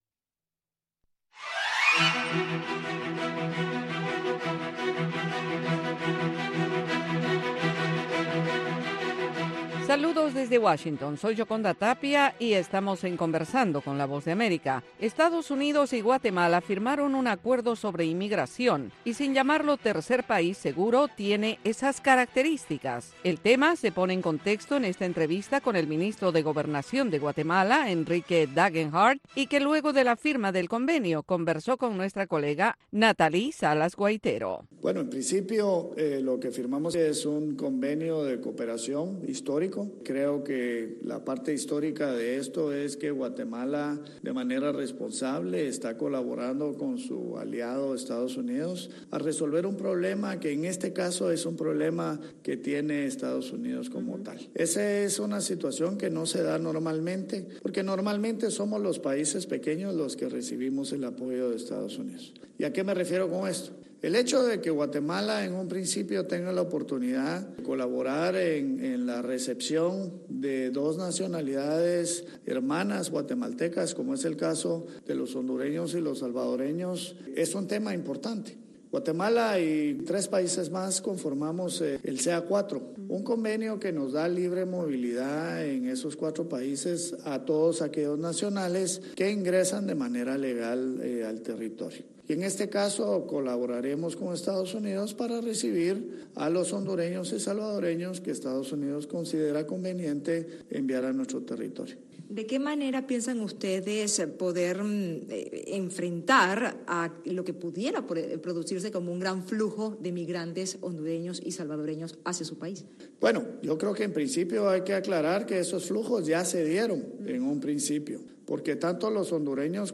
La Voz de América entrevista, en cinco minutos, a expertos en diversos temas.